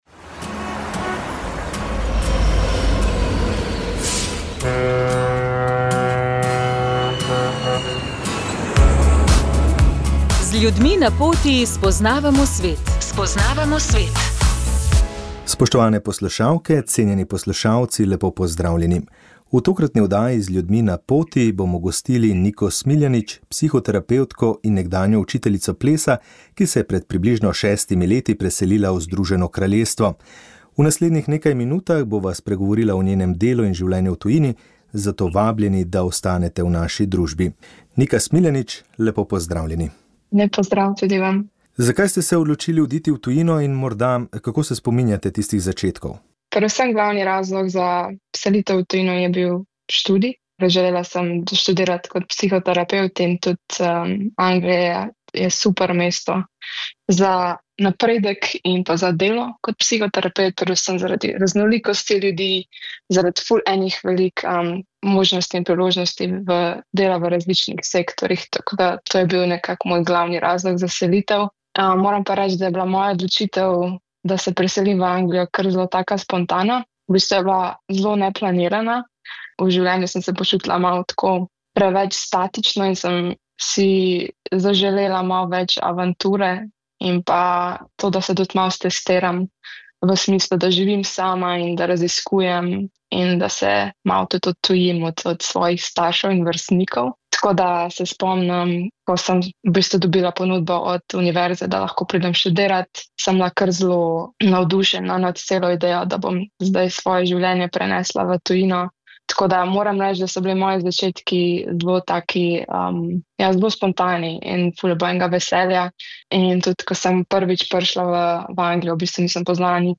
Z začetkom veljavnosti novele zakona o pravilih cestnega prometa lahko vozniki v določenih križiščih zavijejo desno tudi ob rdeči luči na semaforju. V katerih primerih je to mogoče in kaj novega še prinaša novela na področju vožnje z električnimi skiroji, kako bo s kaznimi za prehitro vožnjo...? Gost Svetovalnice je bil državni sekretar Aleš Mihelič.